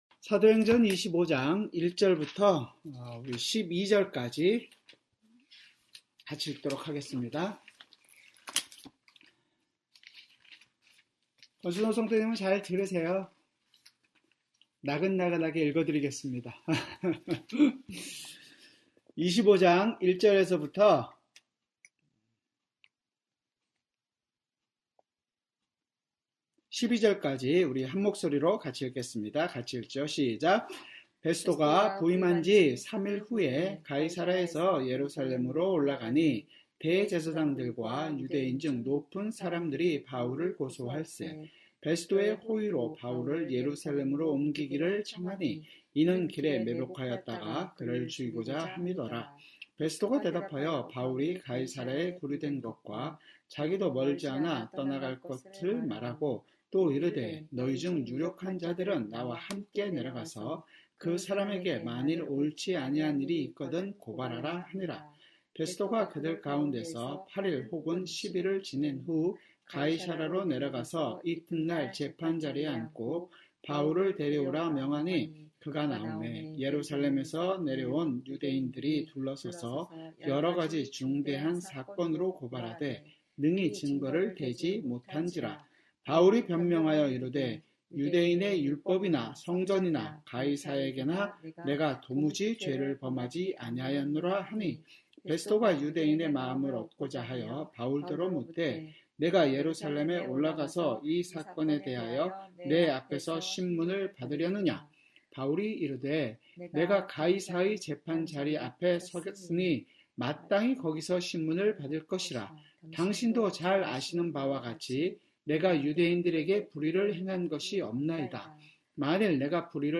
수요성경공부